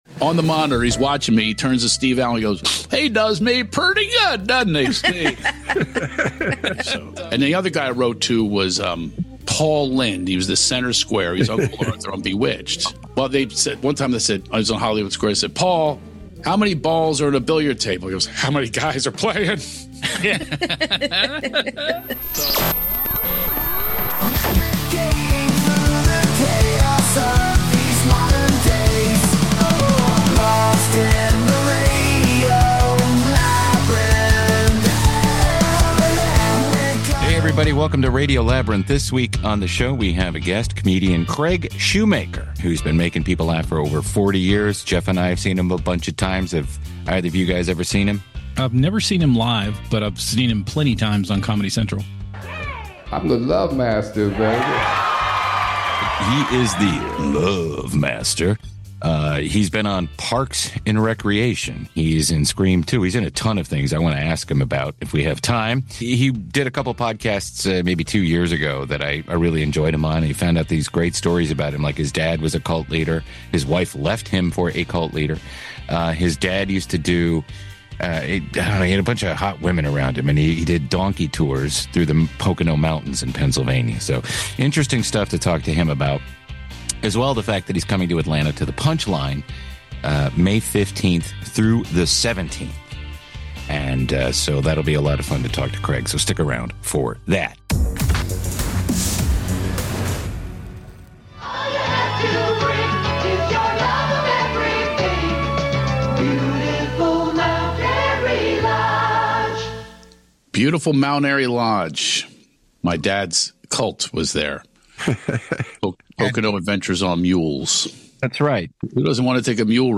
This week on Radio Labyrinth, we sit down with comedy legend Craig Shoemaker — a man who’s been making audiences laugh for over 40 years and somehow turned it into both an art form and a wellness movement.
Guest: Craig Shoemaker